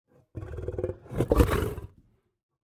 جلوه های صوتی
دانلود صدای شیر 1 از ساعد نیوز با لینک مستقیم و کیفیت بالا
برچسب: دانلود آهنگ های افکت صوتی انسان و موجودات زنده دانلود آلبوم صدای انواع شیر از افکت صوتی انسان و موجودات زنده